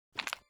sprayer_take_oneshot_002.wav